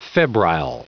Prononciation du mot febrile en anglais (fichier audio)
Prononciation du mot : febrile